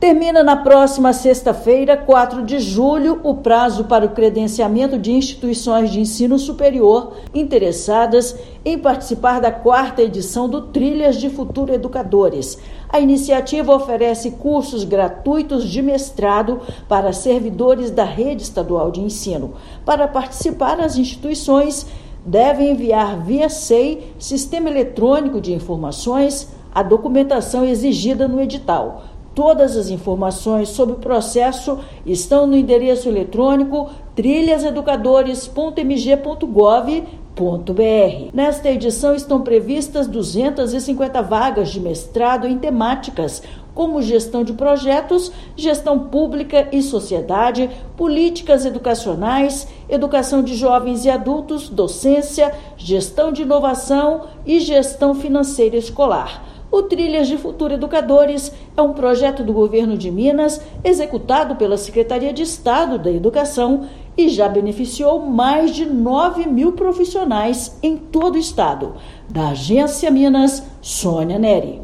Iniciativa é voltada a instituições interessadas em oferecer cursos de mestrado a educadores da rede estadual. Ouça matéria de rádio.